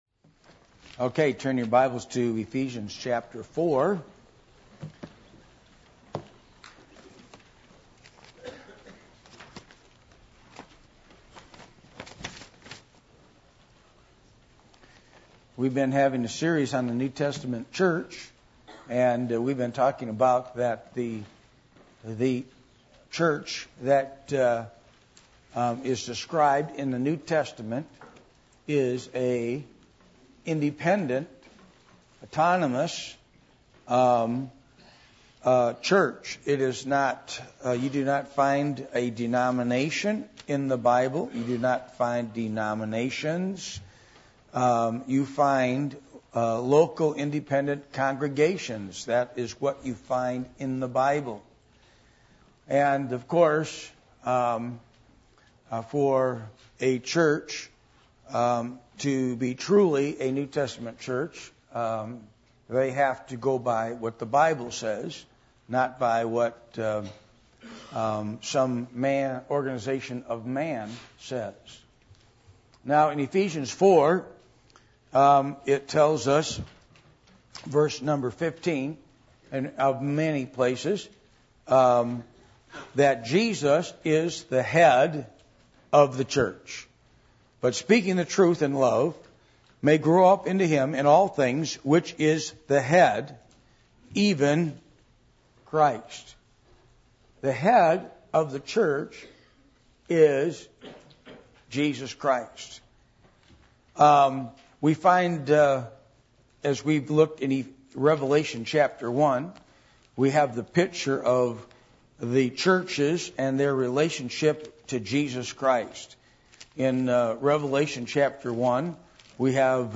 Ephesians 4:15 Service Type: Midweek Meeting %todo_render% « What Is A Christian Culture?